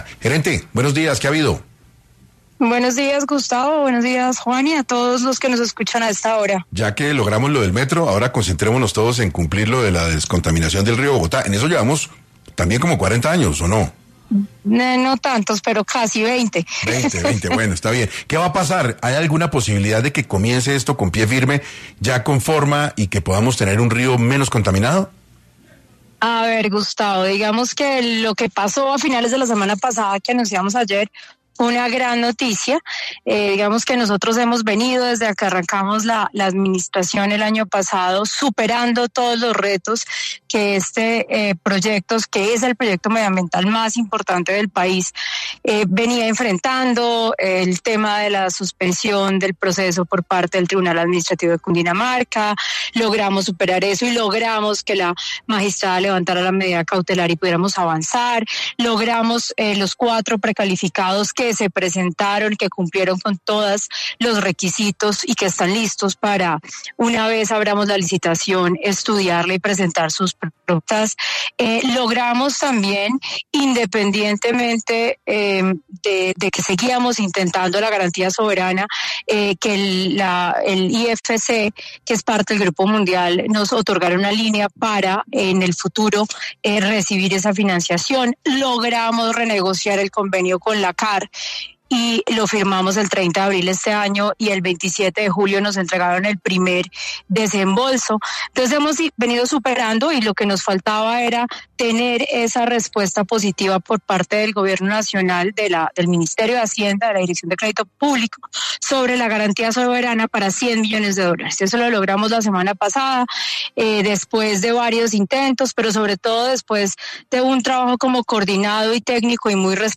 La gerente del Acueducto de Bogotá, Natasha Avendaño, habló en 6AM del avance para construir la segunda Planta de Tratamiento de Aguas Residuales de Latinoamérica.